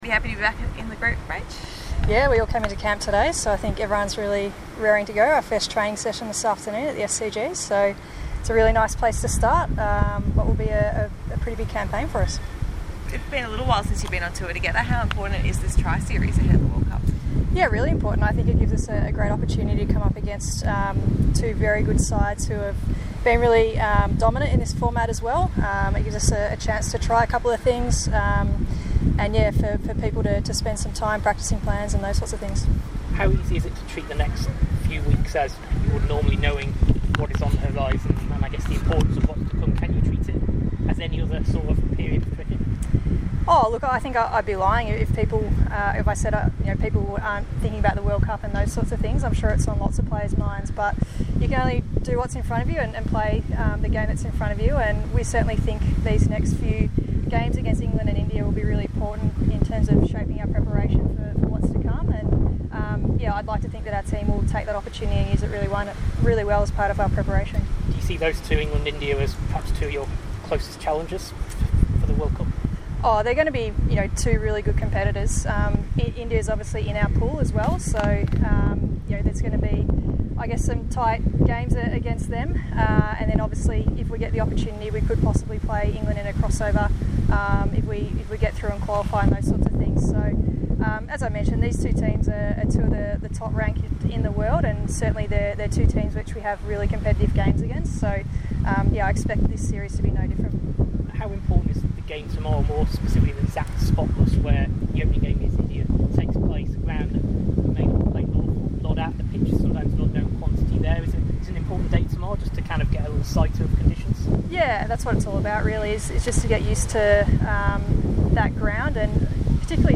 Rachael Haynes vice-captain spoke to media prior to training for the T20 Tri-Series